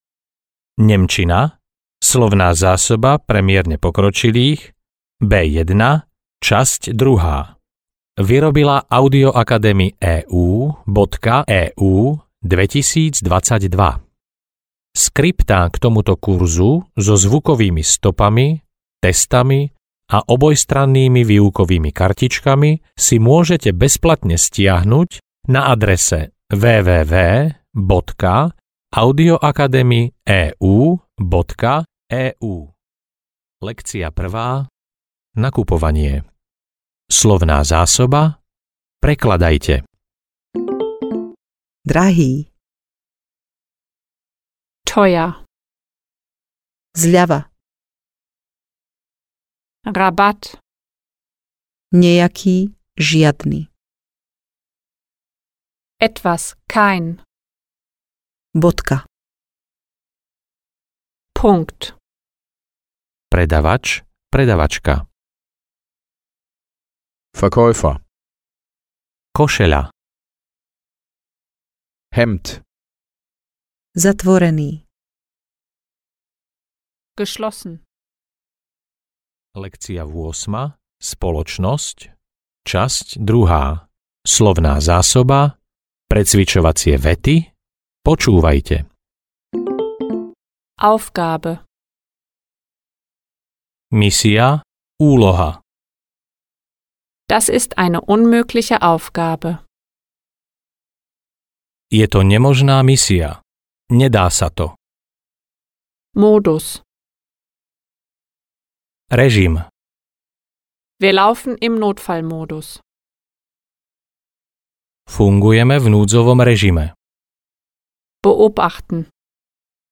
Nemčina pre mierne pokročilých B1, časť 2 audiokniha
Ukázka z knihy